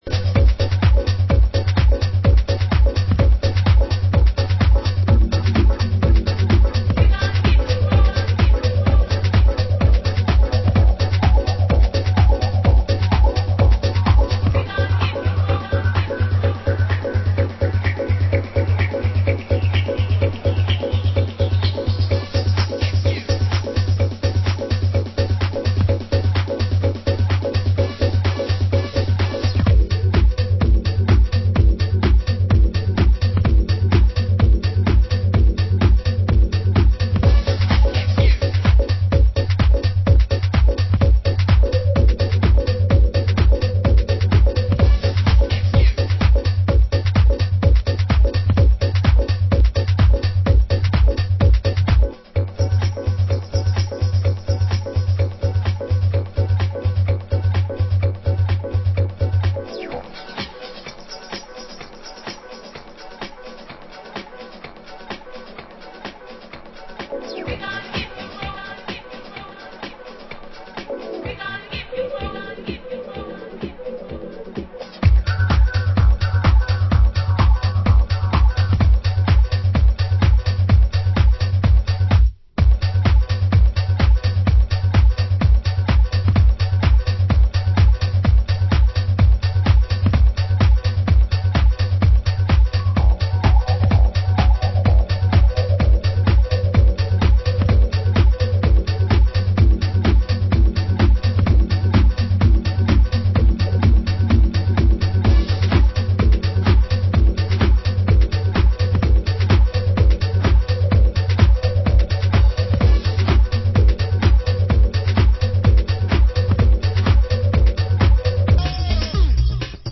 Genre: French House